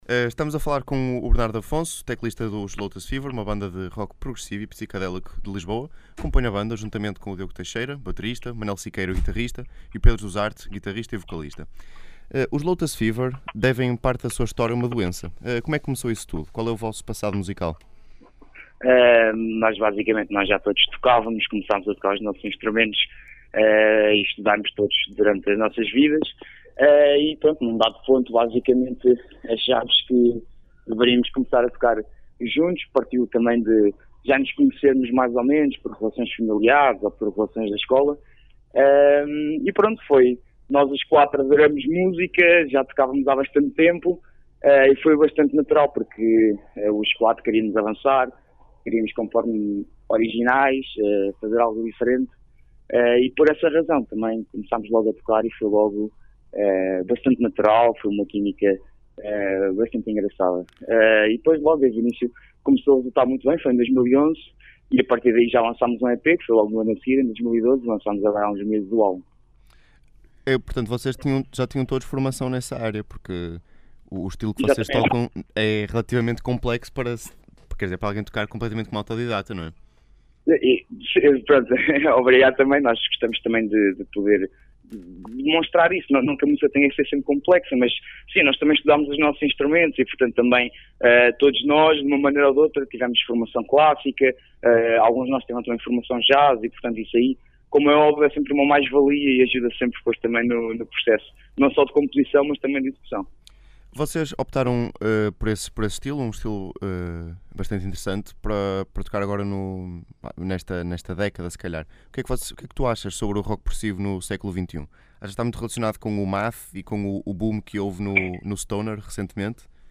Entrevista Lotus Fever - Engenharia Rádio
entrevista-lotus-fever.mp3